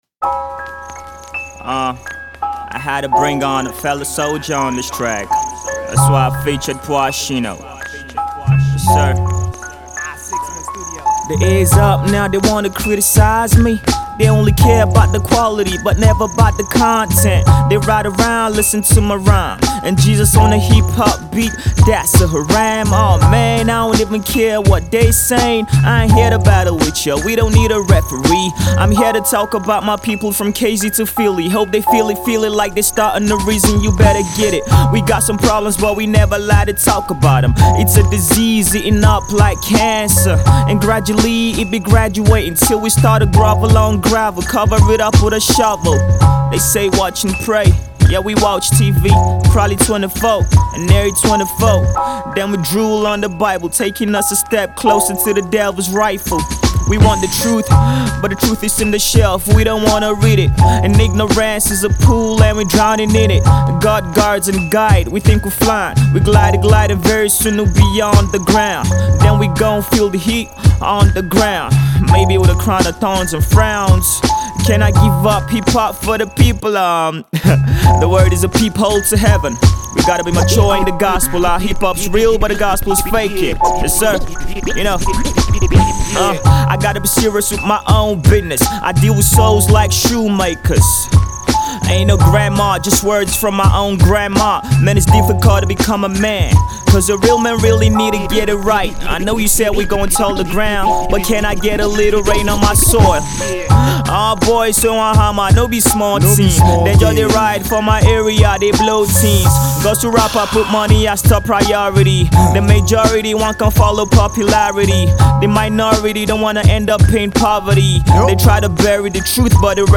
This hard hip hop tune